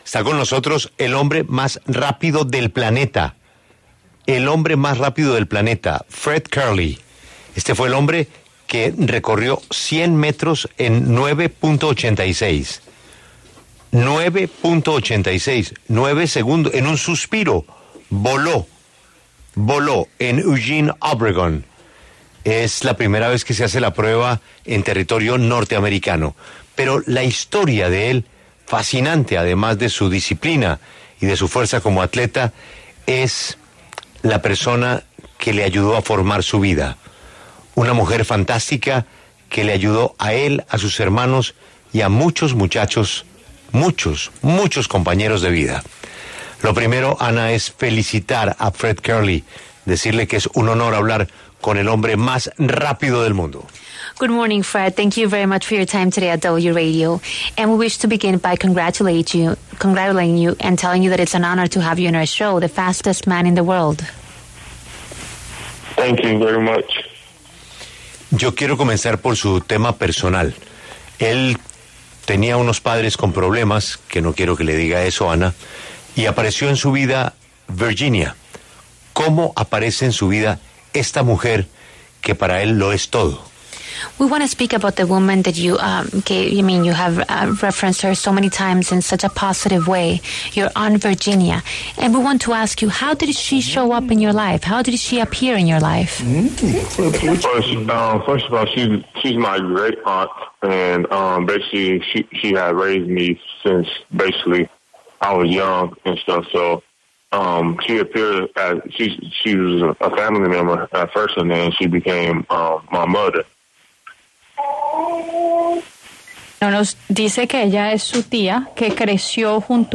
Fred Kerley, atleta olímpico de EE.UU., pasó por los micrófonos de La W para hablar sobre su pasión por el deporte, sus inicios y sus objetivos en el atletismo.